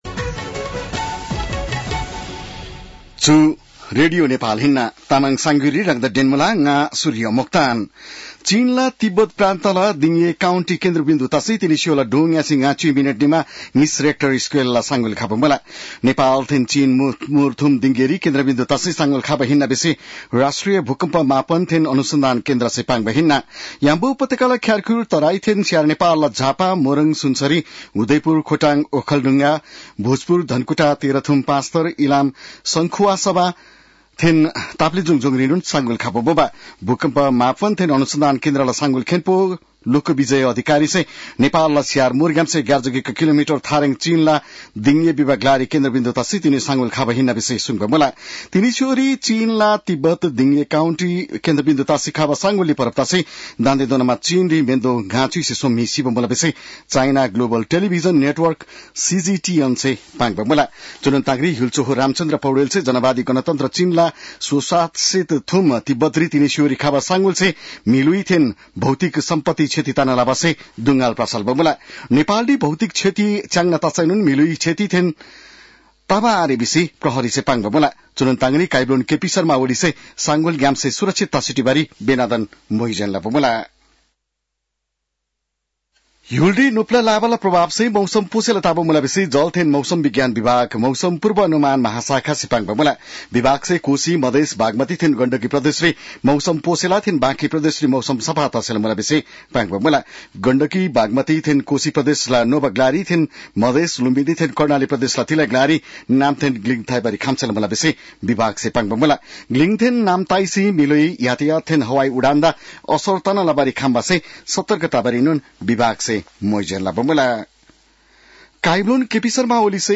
तामाङ भाषाको समाचार : २४ पुष , २०८१
Tamang-news-9-23.mp3